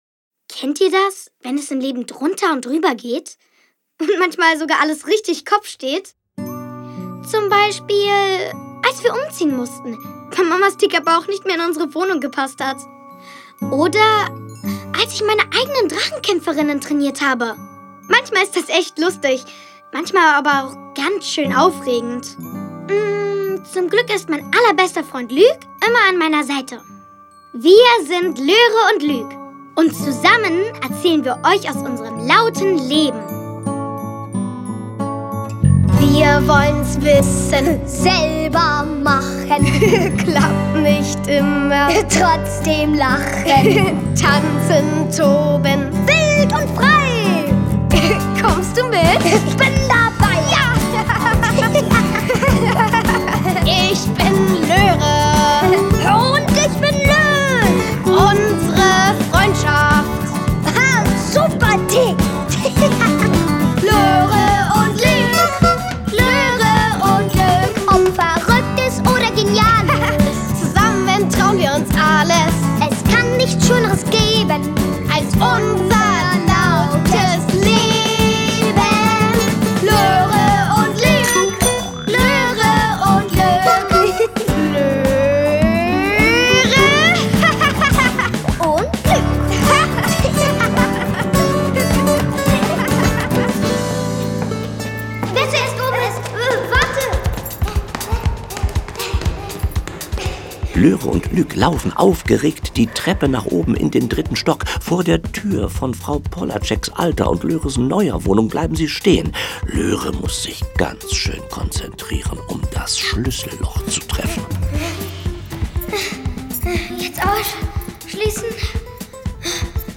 Folge 1: Wir ziehen um / Folge 2: Ich gehe allein Brötchen holen Hörspiele mit Ilja Richter u.v.a.